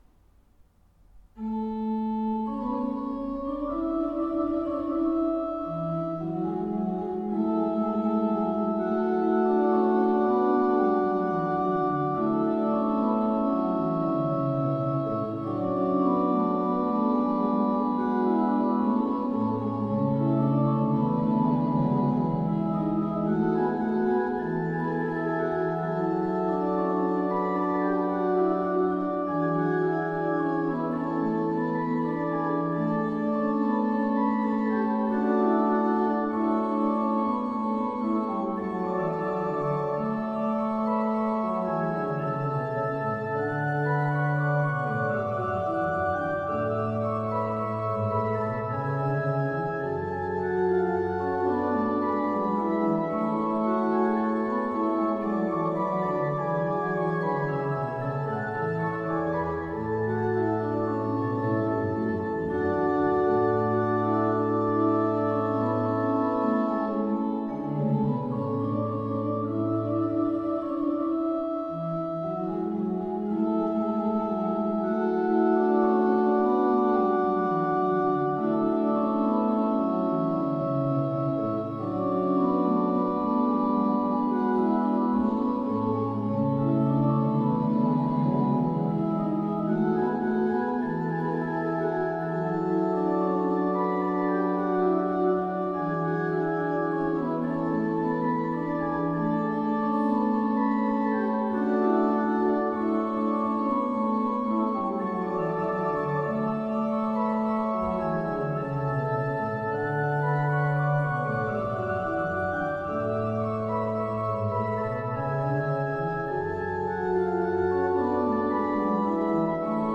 Hlučín, kostel sv. Jana Křtitele - varhany